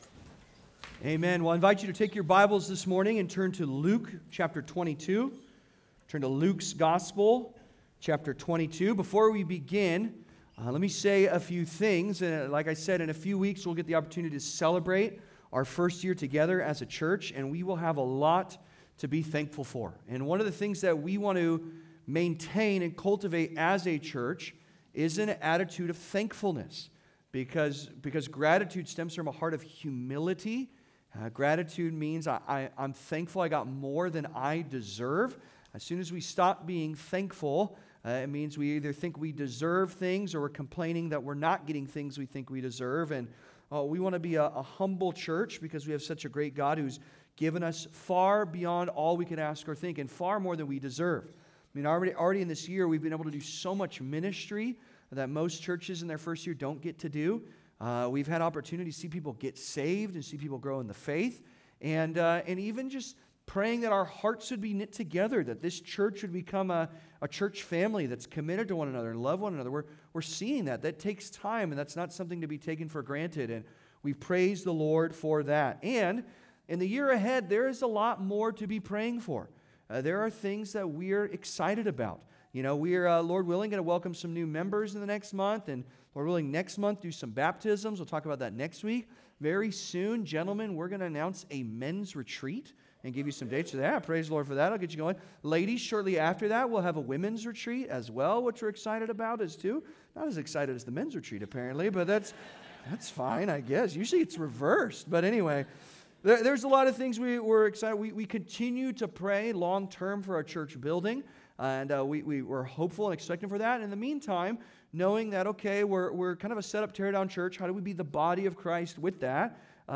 Great Danger, Greater Shepherd (Sermon) - Compass Bible Church Long Beach